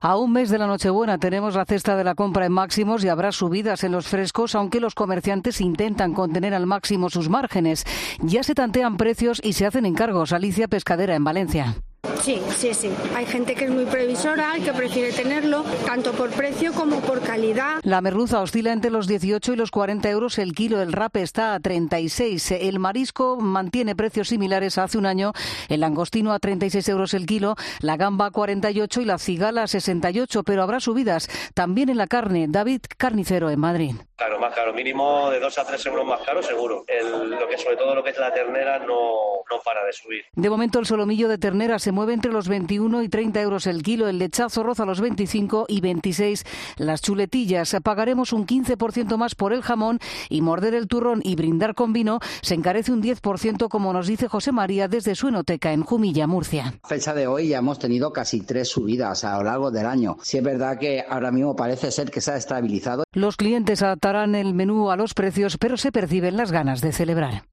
Informe COPE